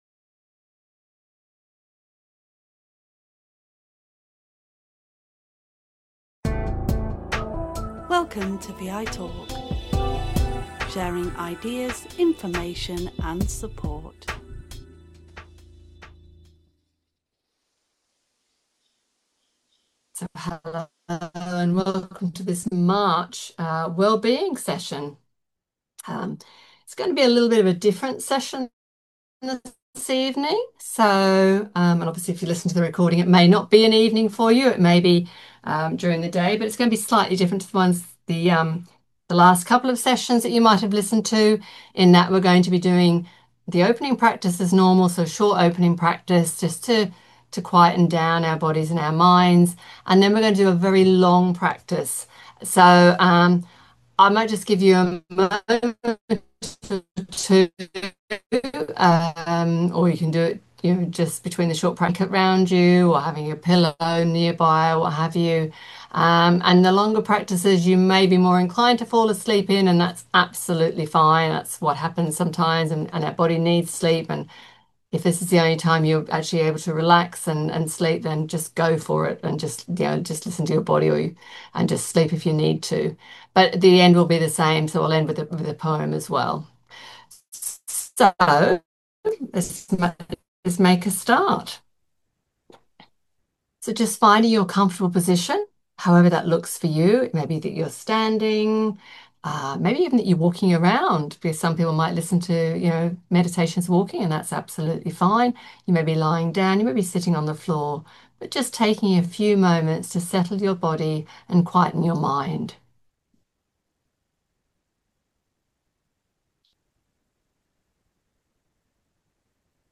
Wellbeing takes place on the third Monday at 8pm over Zoom, the session lasts an hour. All sessions are recorded and shared in VI Talk Wellbeing & Lifestyle.